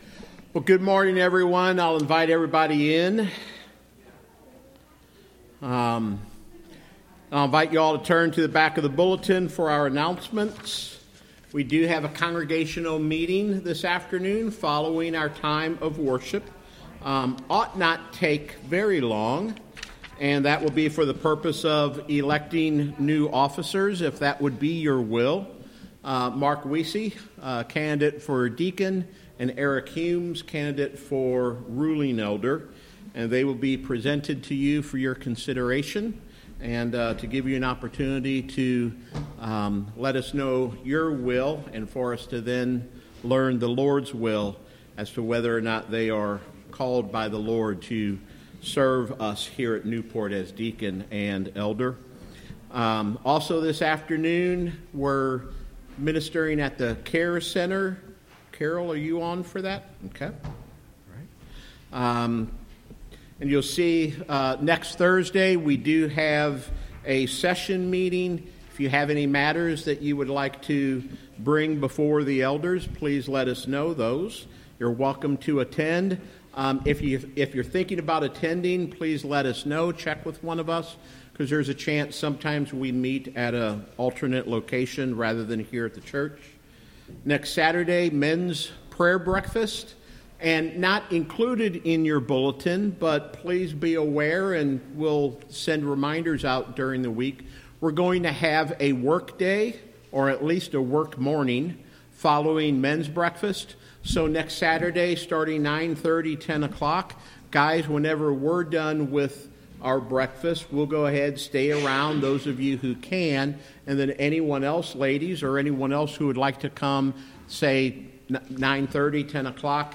Worship Service
Welcome and Announcements
Piano
Congregation Singing
Prayers of Confession and Repentance